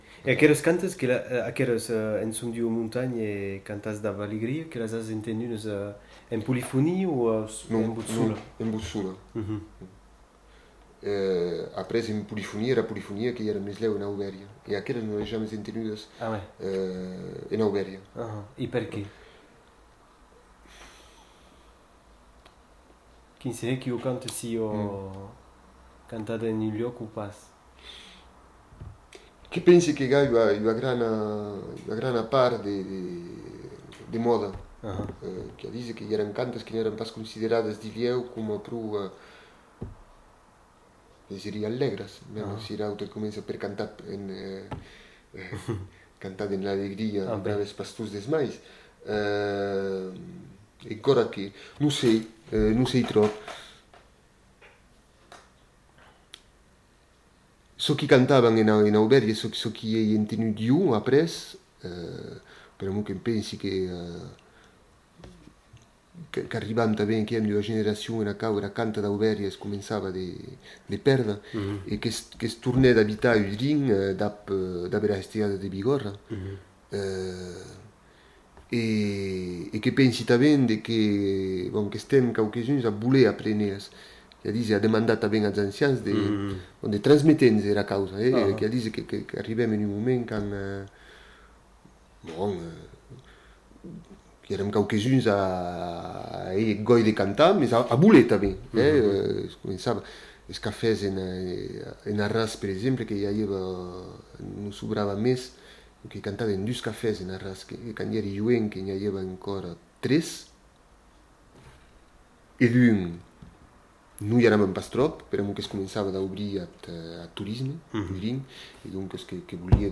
Aire culturelle : Bigorre
Lieu : Ayzac-Ost
Genre : chant
Effectif : 1
Type de voix : voix d'homme
Production du son : chanté
Notes consultables : En début de séquence, le chanteur raconte à quelles occasions, il interprète ce chant.